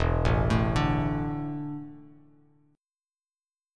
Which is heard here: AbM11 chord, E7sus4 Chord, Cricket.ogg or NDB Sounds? E7sus4 Chord